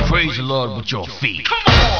Christian Teens C&MA Youth Ministries Bible Answers LIFE '98 C&MA Youth Newsletter Listen to some tunes while you surf A message from Christian Recording Artist Carman: Click Here!!!